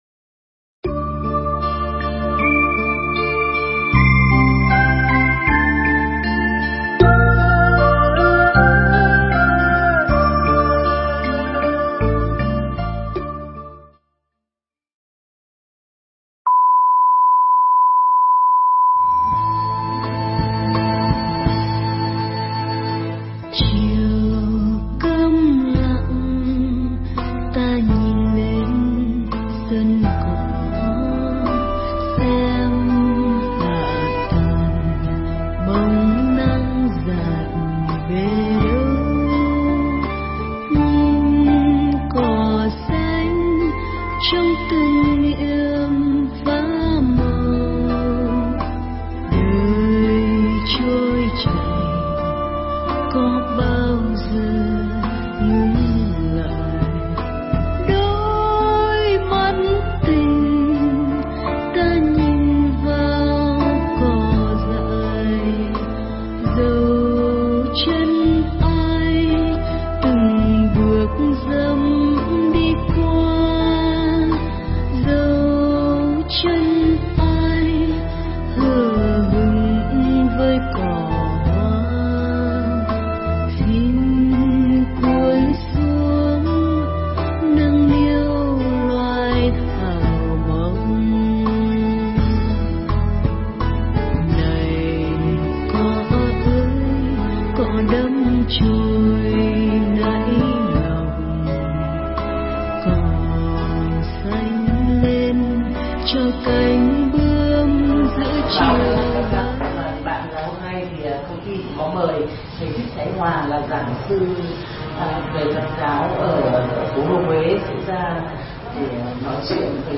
Pháp thoại Những Yếu Tố Giúp Ta Thành Tựu Sự Nghiệp
giảng cho ban lãnh đạo và nhân viên công cổ phần Chuyển Phát Nhanh (Hà Nội)